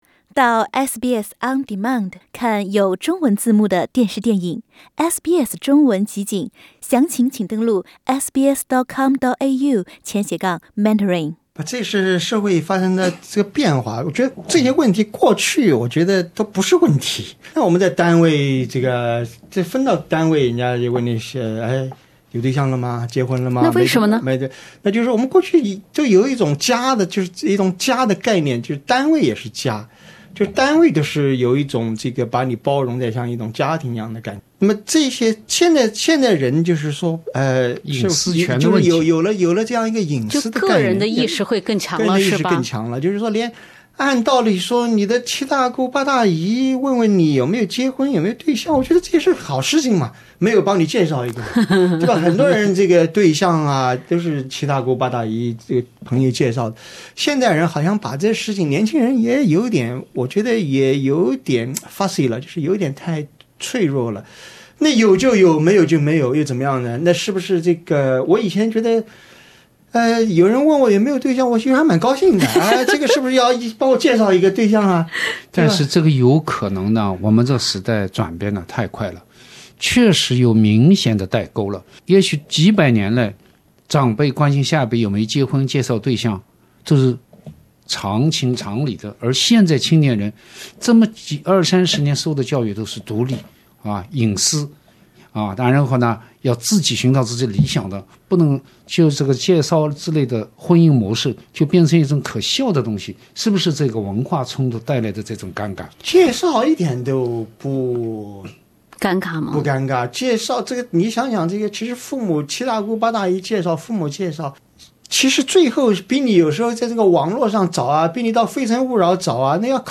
之所以一些人过节感到焦虑，只不过是过节在人集中起来的同时，也自然把很多问题和矛盾集中体现出来了。 欢迎收听SBS 文化时评栏目《文化苦丁茶》，本期话题是：缺年货，还是缺年味？请点击收听。